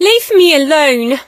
rosa_hit_04.ogg